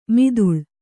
♪ miduḷ